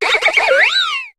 Cri de Laporeille dans Pokémon HOME.